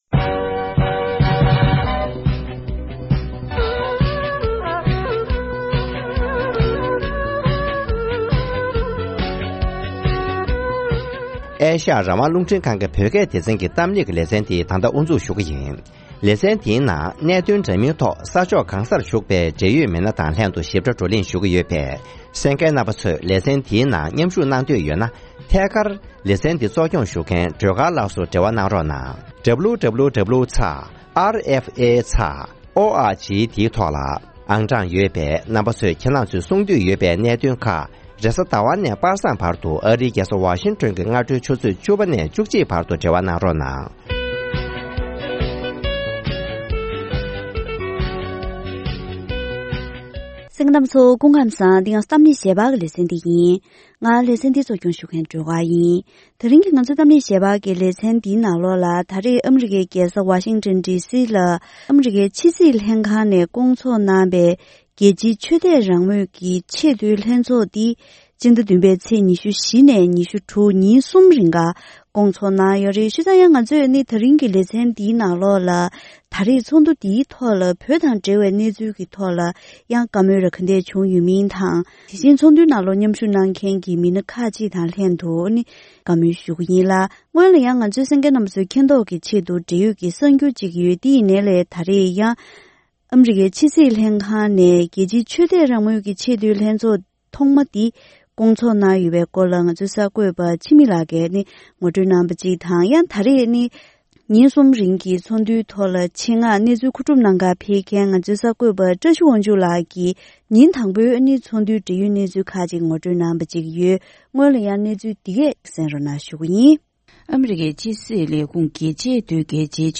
༄༅། །ཐེངས་འདིའི་གཏམ་གླེང་ཞལ་པར་ལེ་ཚན་ནང་།